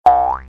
gialboing Meme Sound Effect
gialboing.mp3